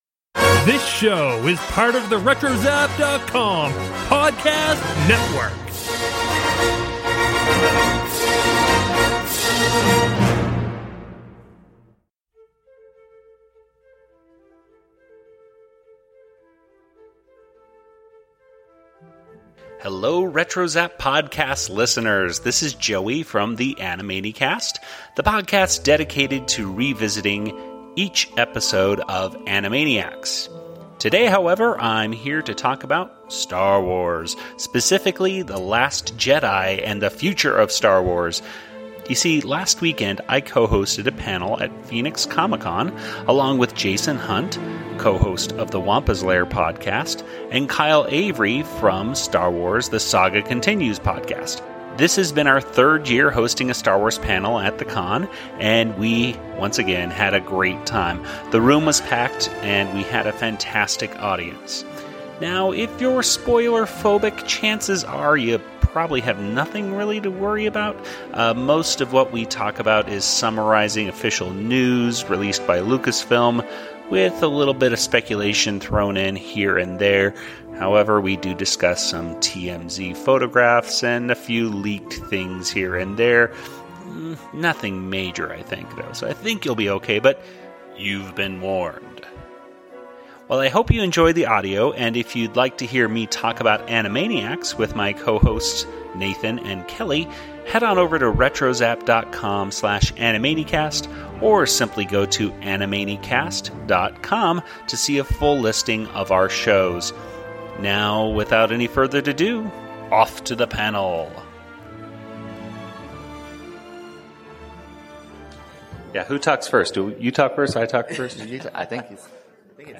From Phoenix Comicon 2017 — It’s “The Last Jedi” panel featuring The Animanicast and special guests!